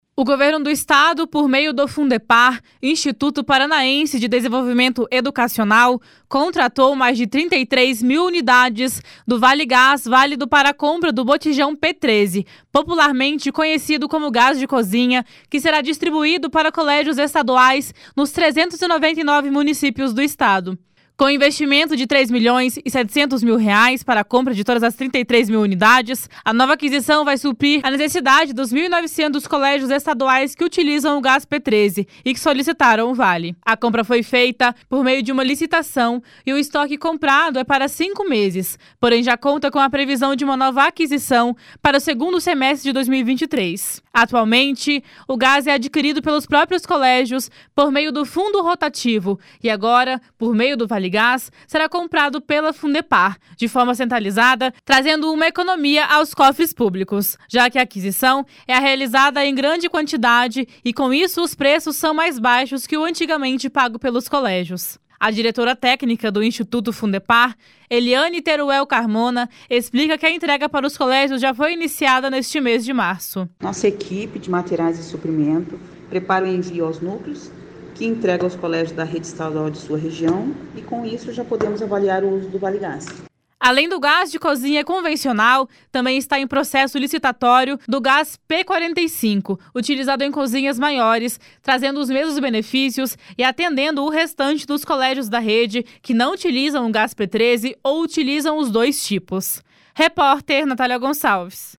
Além do gás de cozinha convencional também está em processo licitatório do gás p45 utilizado em cozinhas maiores, trazendo os mesmos benefícios e atendendo o restante dos colégios da rede que não utilizam o gás p13 ou utilizam os dois tipos. (Repórter: